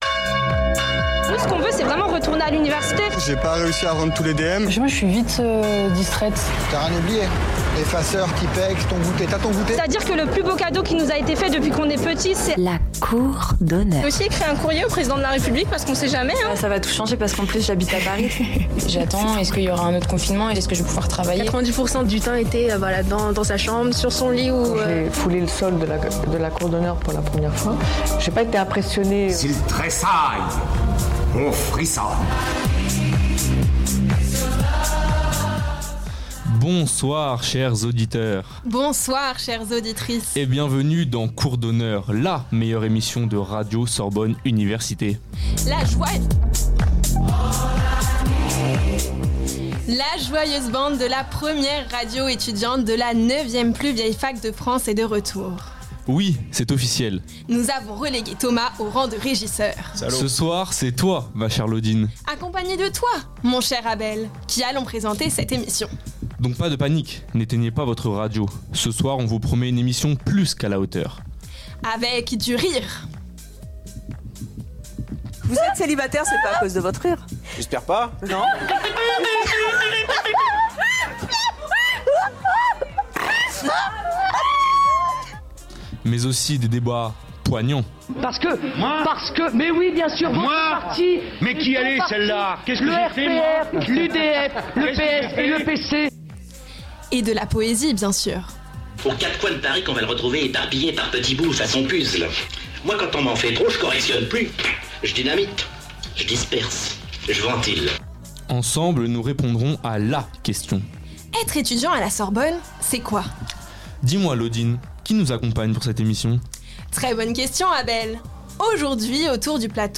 La vie étudiante à la Sorbonne sous toutes ses facettes. Anecdotes sur la plus vieille fac de France, interview d'enseignants, reportages sur le quotidien des étudiants, carte postale sonore des lieux emblématique de l'université.